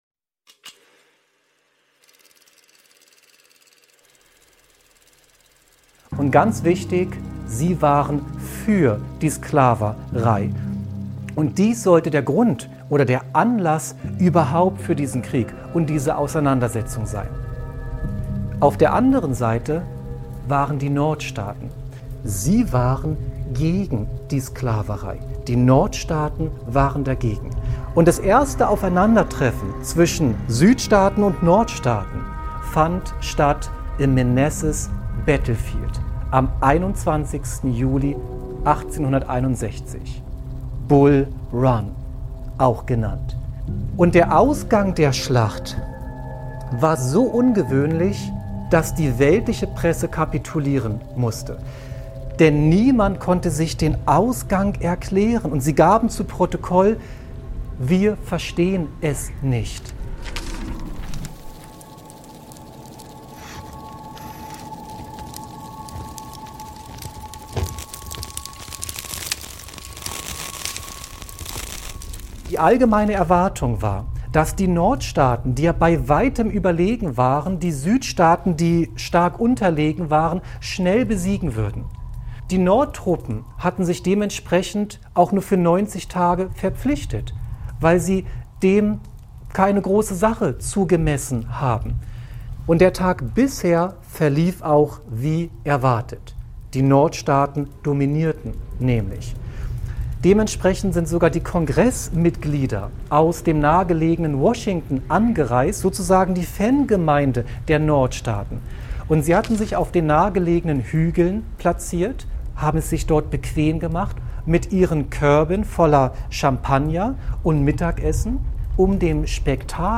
In einem tiefgründigen Vortrag wird die unerklärliche Niederlage der Nordstaaten in der ersten Schlacht von Bull Run ergründet. Während die Welt der Presse ratlos bleibt, eröffnet sich eine geistliche Dimension: War es Gott, der den Ausgang bestimmte? Welche Rolle spielen Engel in Kriegen?